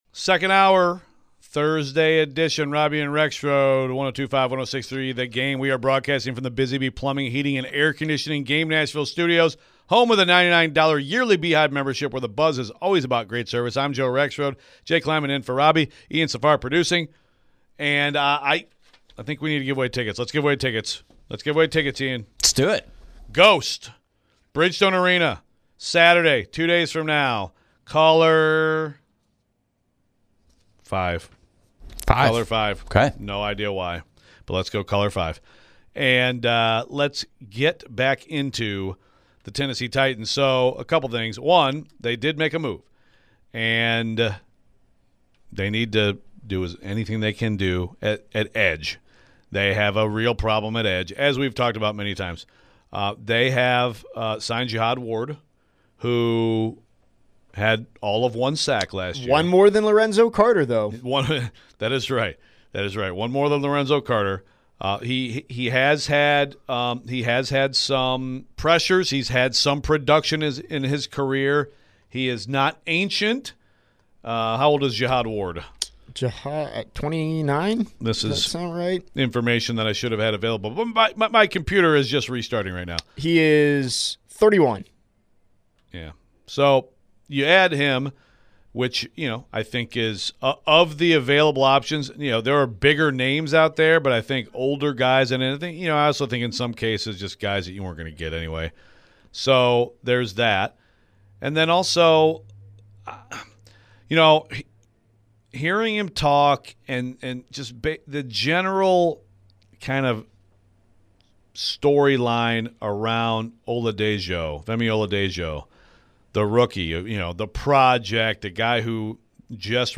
Reports were out last night that the Titans are bringing in OLB Jihad Ward and what does that mean for the OLB room? We take more phones. We get the latest out of Knoxville and news and notes around the Vols.